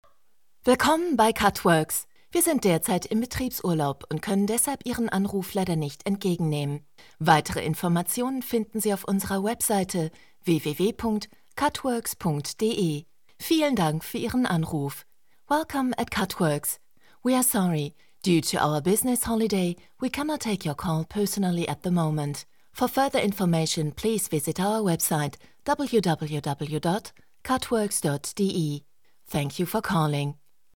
Finden Sie hier Hörbeispiele, Hörproben unserer Sprecher und Sprecherinnen und eine Auswahl an Audio-Demos.
deutsch-englische Ansage "Betriebsferien"